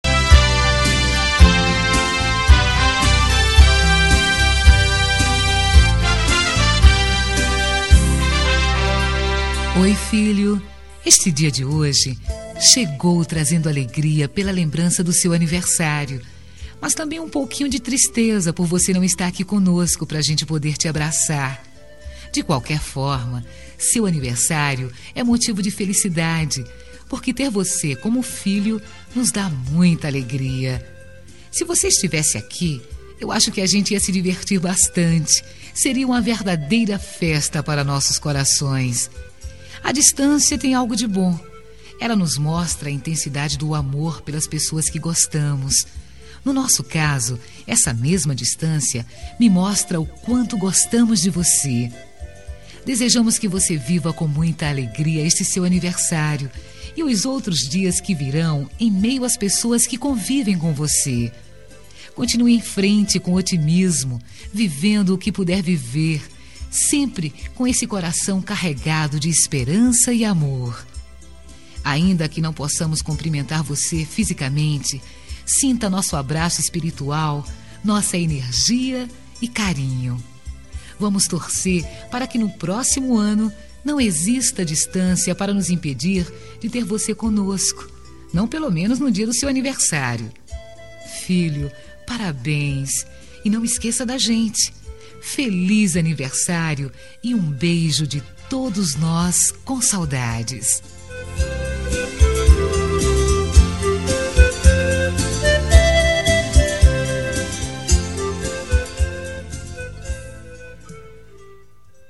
Aniversário de Filho – Voz Feminino – Cód: 5215 – Plural – Distante
5215-aniver-filho-fem-distante-plural.m4a